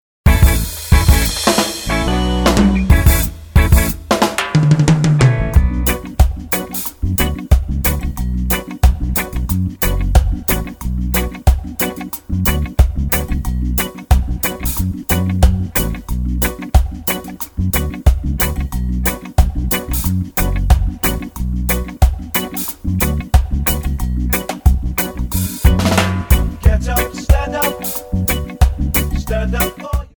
Tonart:Bm Multifile (kein Sofortdownload.
Die besten Playbacks Instrumentals und Karaoke Versionen .